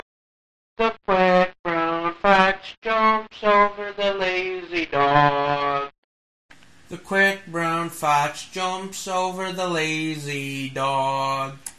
make my voice sound like I am ill
first I used a “telephone” VST and slid it all the way to the right
then I used a plugin included in the LADSPA plugins pack called “Valve Saturation” and slid both sliders all to the right… here is what came out of it attached…
first is the sick voice followed by my normal voice… see the comparison???